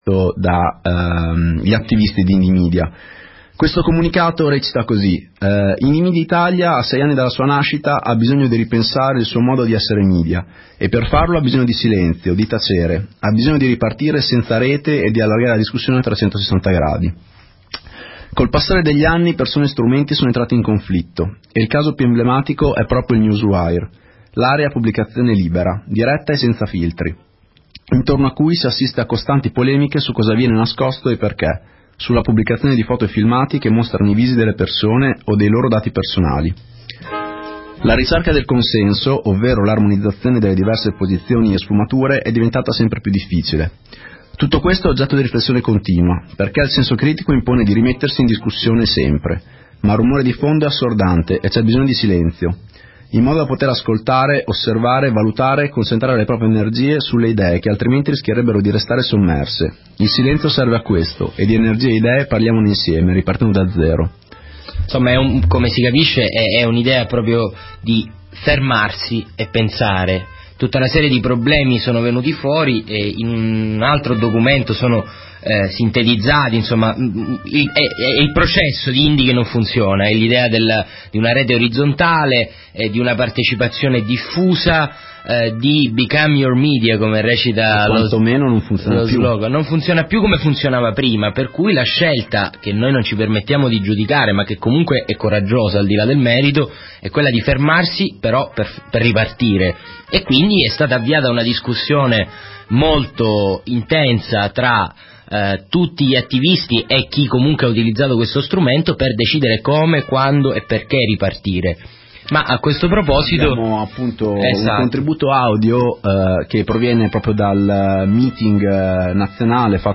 Newsletter Ricerca vagamondo tutti gli appuntamenti di vag Scarica Vagamondo del 9 dicembre '06 Scarica la puntata del 9 dicembre '06 Vagamondo / L'autocoscienza dei media indipendenti (audio mp3) Indymedia chiude per ricominciare: ascoltiamo l'intervista ad un mediattivista che ha partecipato al meeting che ha decretato l'azzeramento dell'Indipendent Media Center italiano perché possa ripensare il suo modo di essere media, ricominciando da una mailing list aperta a tutti. Parliamo poi della situazione a Bologna e in Italia delle telestreet (tv di strada).